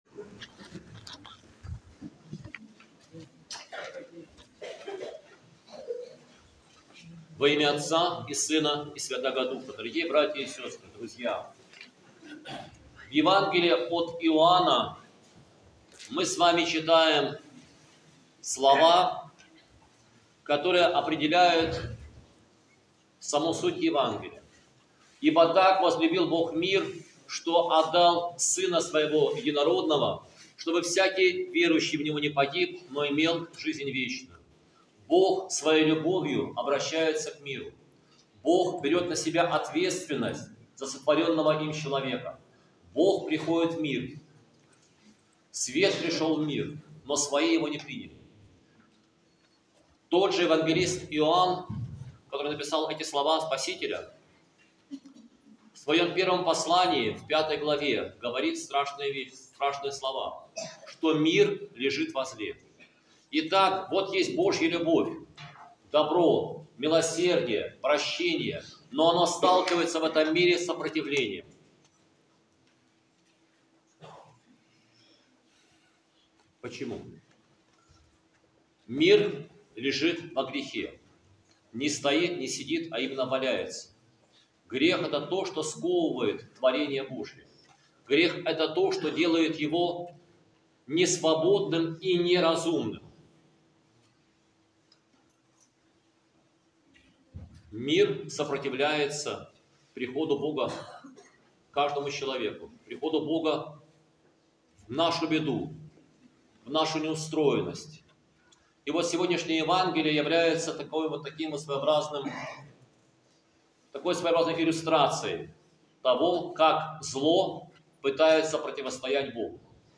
Проповедь с Божественной Литургии 10.11.2024